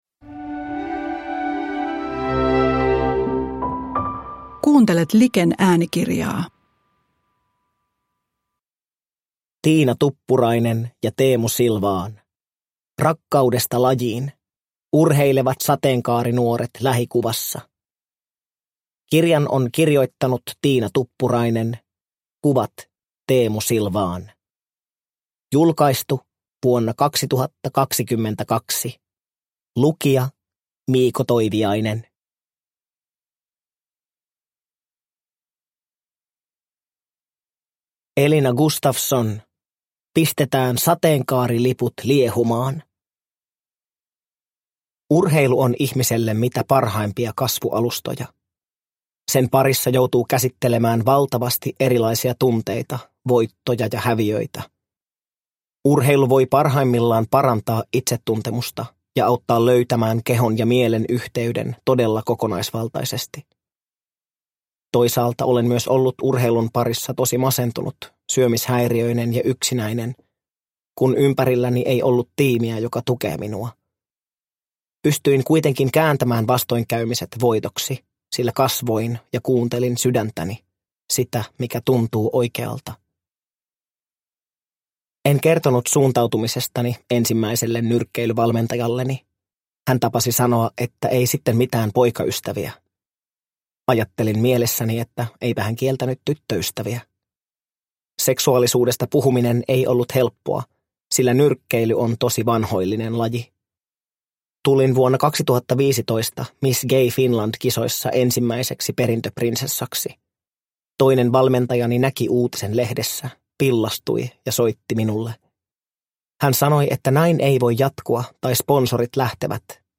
Rakkaudesta lajiin – Ljudbok – Laddas ner